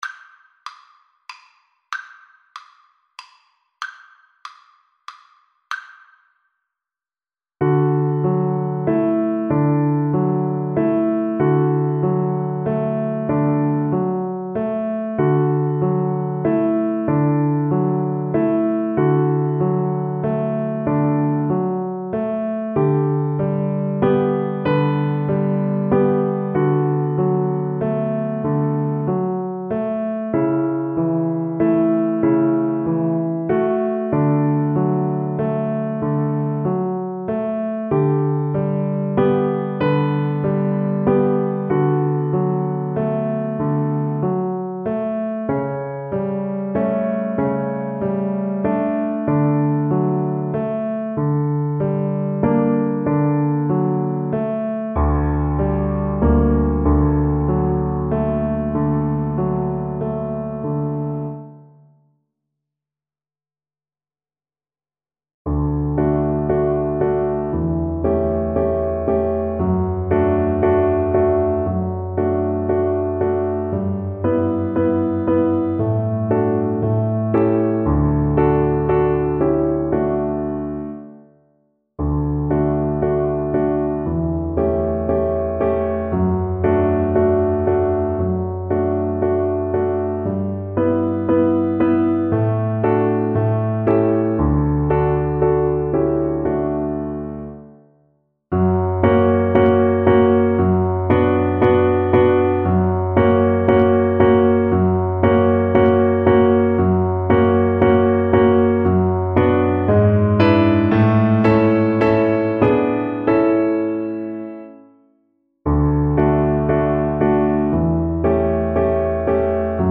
Play (or use space bar on your keyboard) Pause Music Playalong - Piano Accompaniment Playalong Band Accompaniment not yet available transpose reset tempo print settings full screen
Violin
D major (Sounding Pitch) (View more D major Music for Violin )
Andante sostenuto (.=48)
Classical (View more Classical Violin Music)